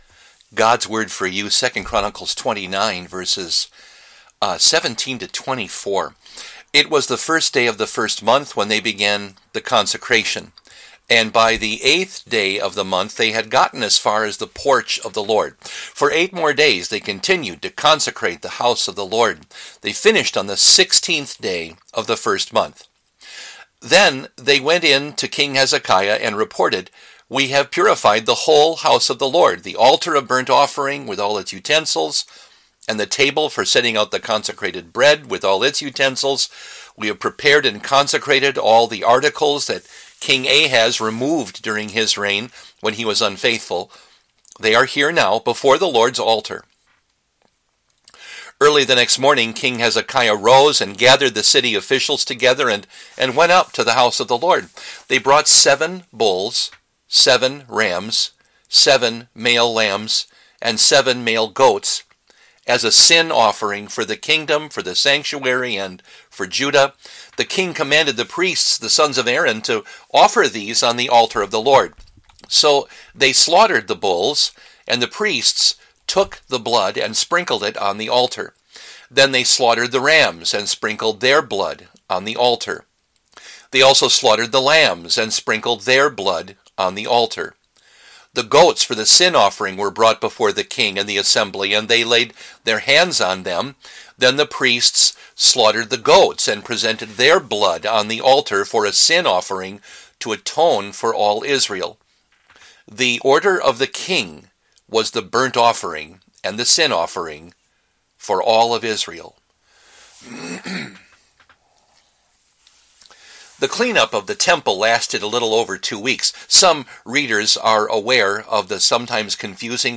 A daily devotion